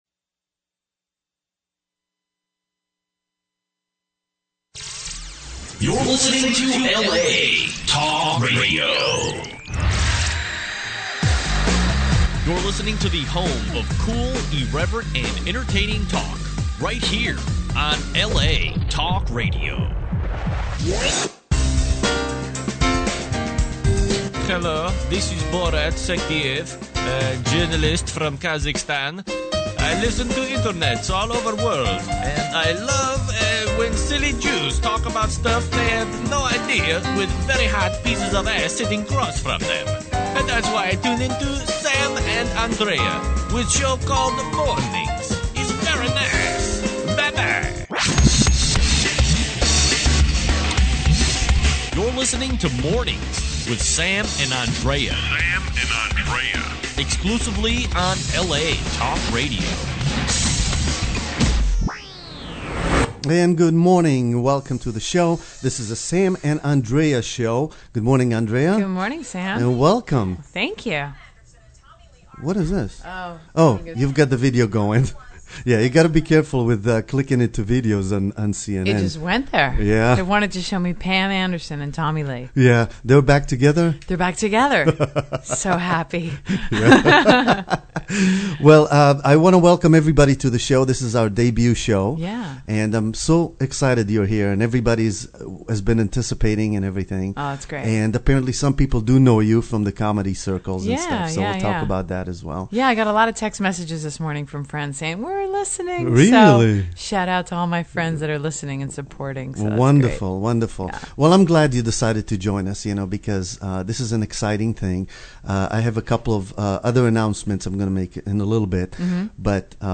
MORNINGS serves a variety of the day's topics with funny and smart commentary, as well as a look into our lives. The show features a breadth of guests and callers to keep you entertained.
Call in live and chat with us on the air.